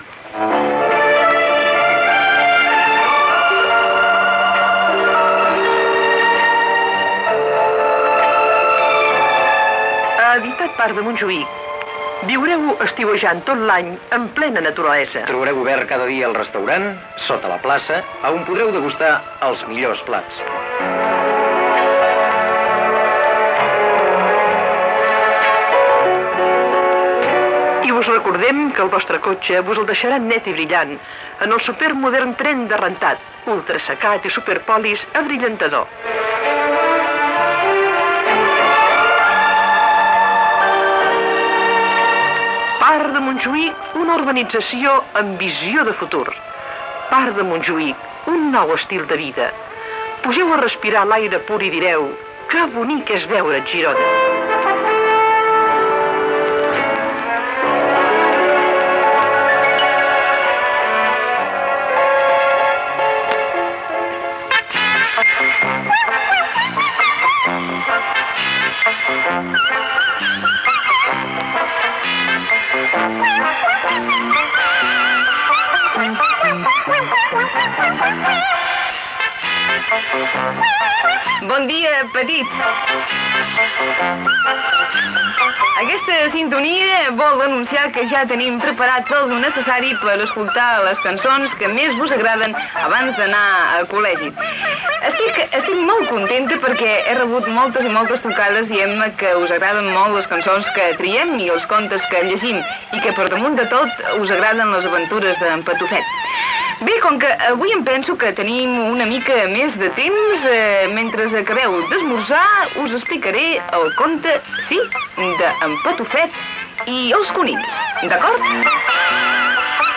Publicitat, presentació del programa i conte "El Patufet i els conills"
Infantil-juvenil